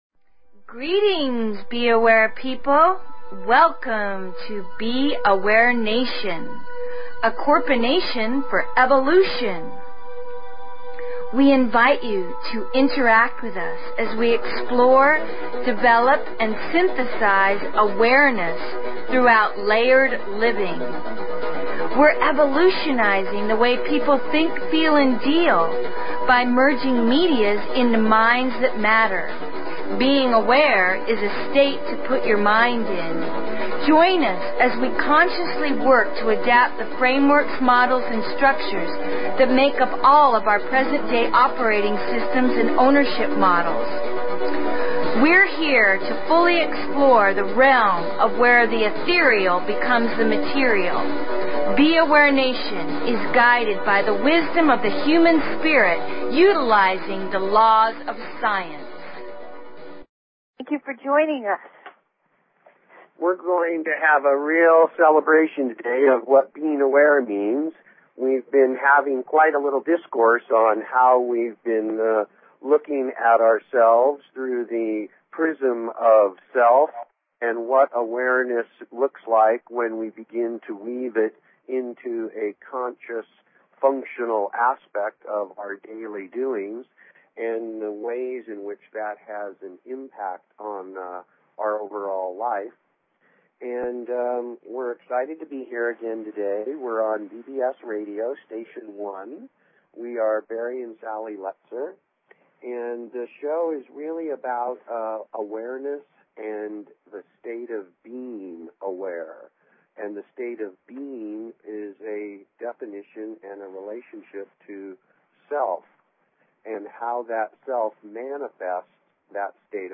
Talk Show Episode, Audio Podcast, B_Aware_Nation and Courtesy of BBS Radio on , show guests , about , categorized as